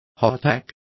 Complete with pronunciation of the translation of hardtack.